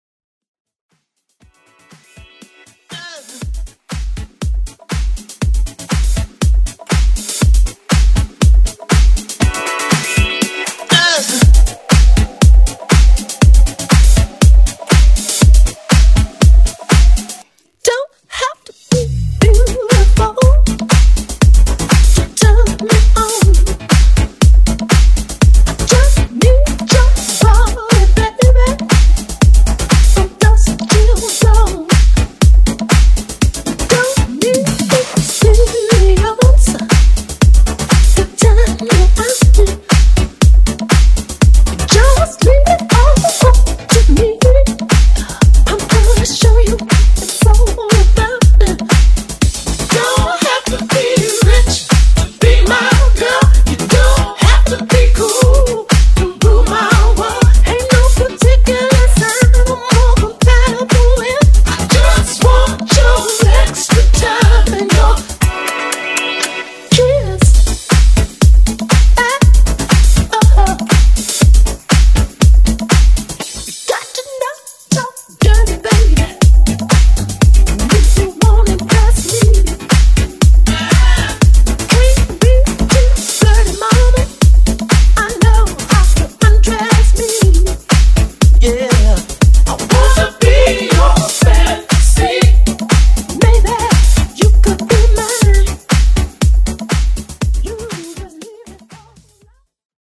Genres: MASHUPS , REGGAETON , TOP40
Clean BPM: 100 Time